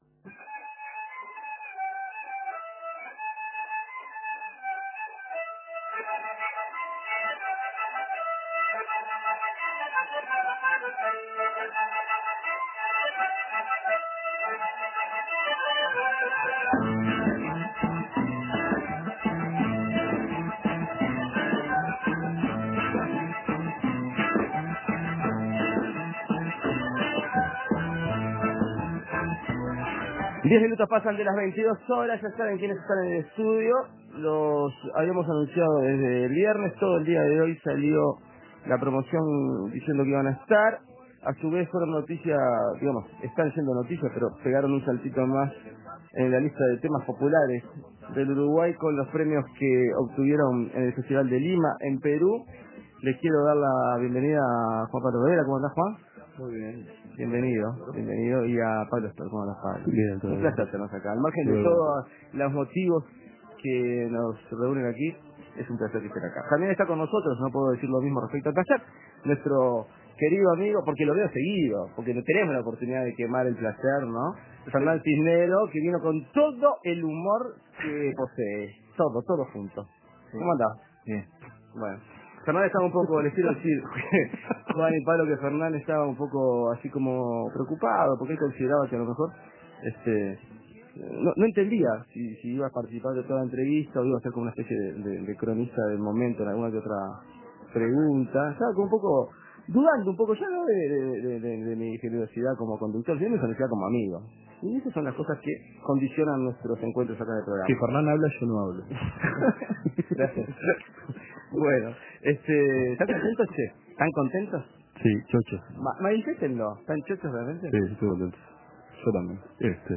Entrevista a Juan Pablo Rebella y Pablo Stoll (foto), directores de la premiada película Whisky